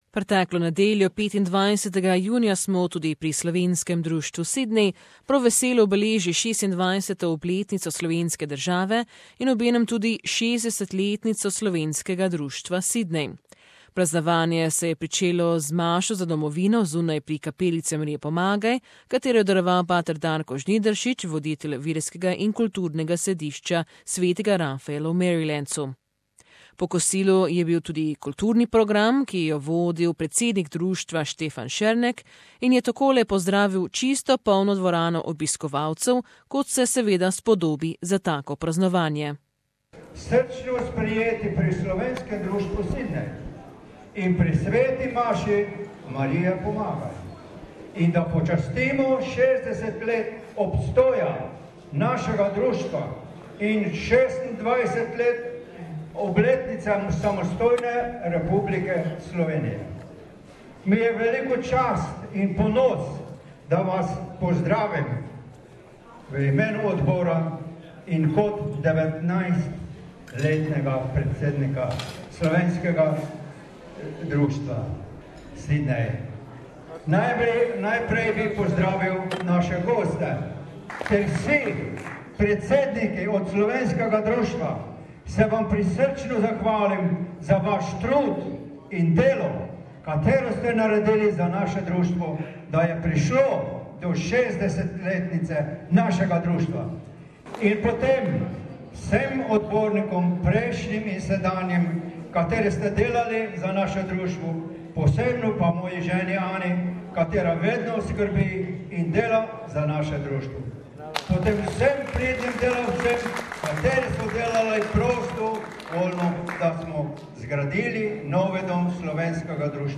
On Sunday June 25, Sydney also celebrated Slovenian National Day, as well as the 60th anniversary of Slovenian Association Sydney. In this feature from the days events, there was much said about Slovenia and its fight for independence, as well as the rich history of the association over the last 60 years.